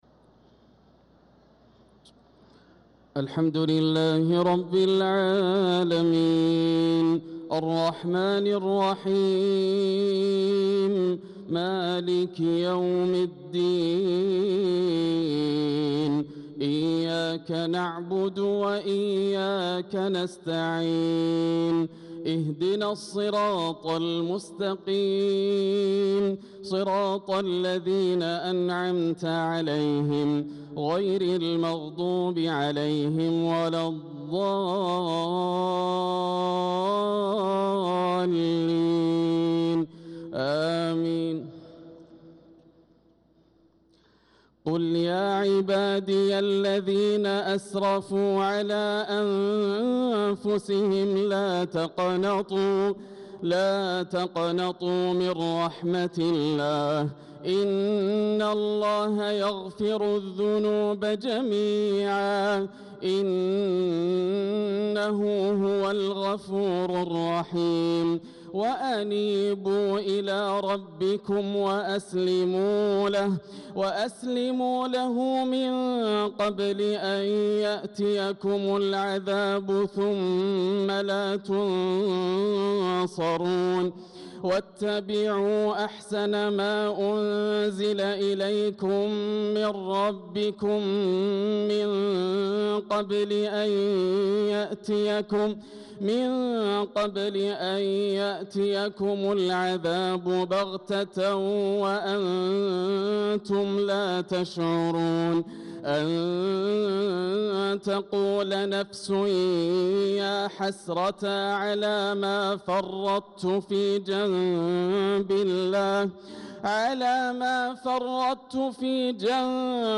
صلاة الفجر للقارئ ياسر الدوسري 12 ذو الحجة 1445 هـ
تِلَاوَات الْحَرَمَيْن .